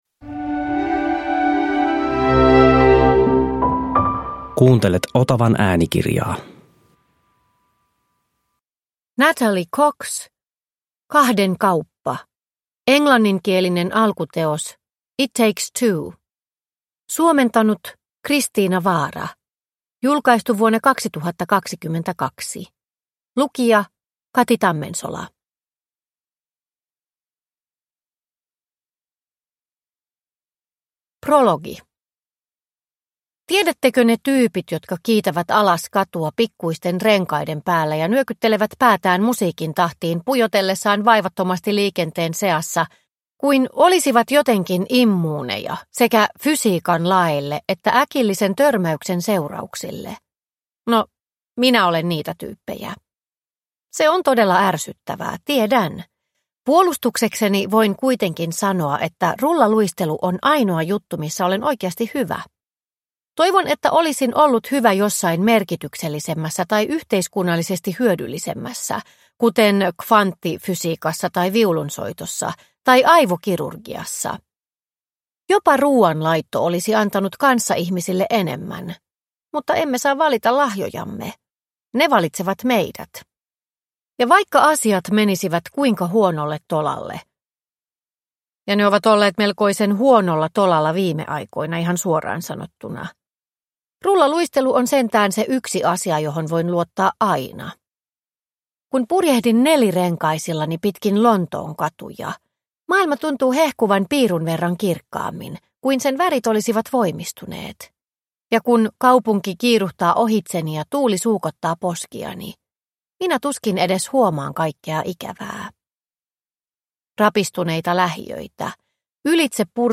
Kahden kauppa – Ljudbok – Laddas ner